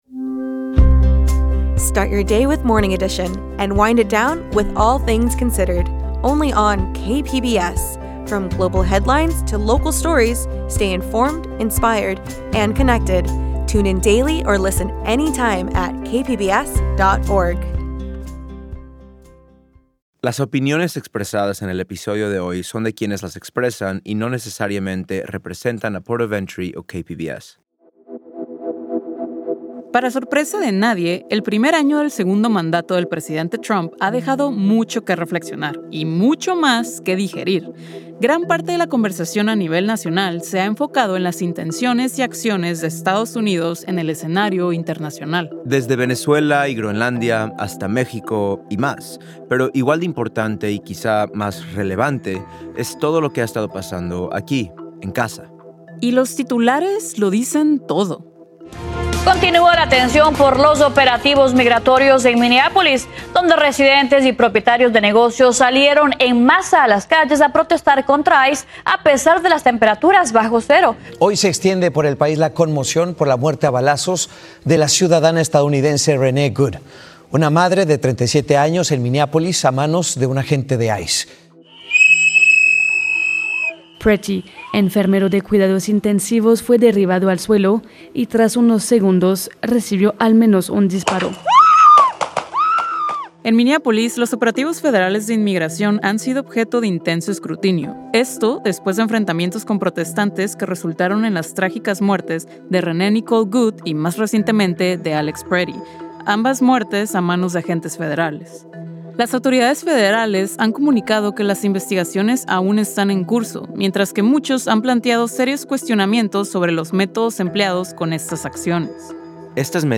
From KPBS Port of Entry tells personal stories from this place stories of love, hope, struggle, and survival from border crossers, fronterizxs, and other people whose lives are shaped around the wall. Rooted in San Diego and Tijuana, we are a transborder podcast for transborder people.